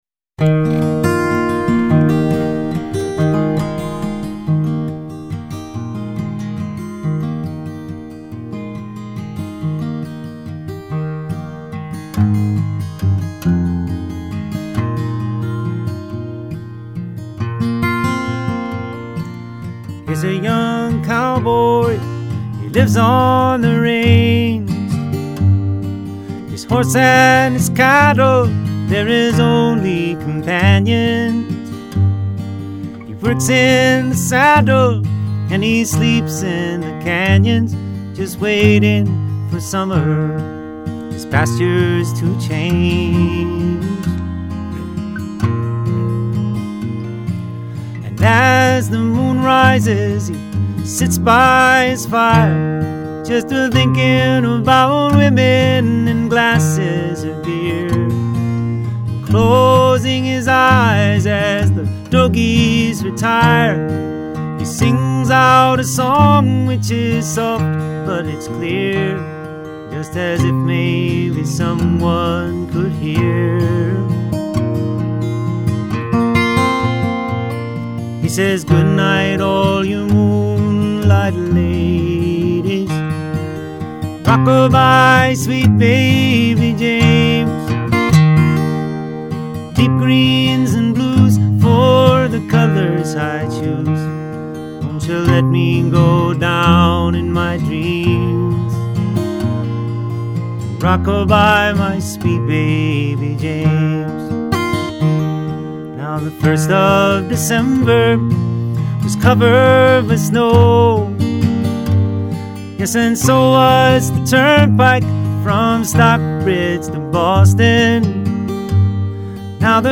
acoustic performance